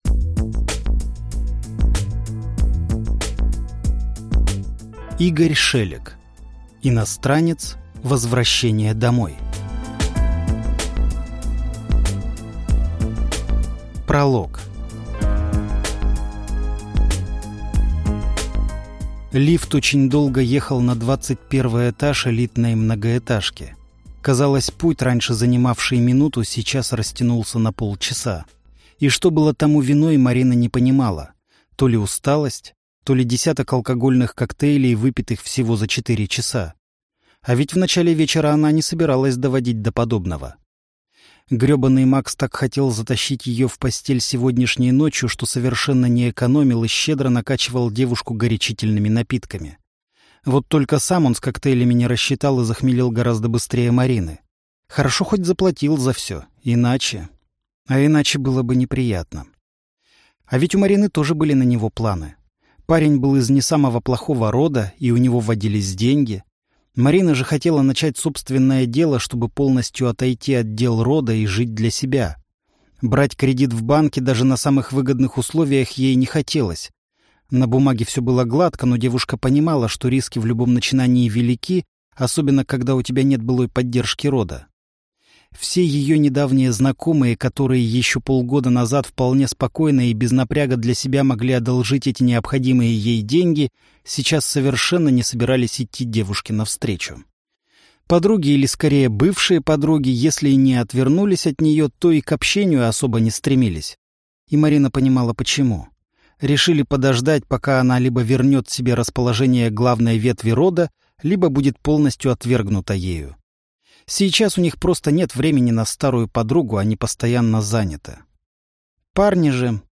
Аудиокнига Иностранец. Возвращение домой | Библиотека аудиокниг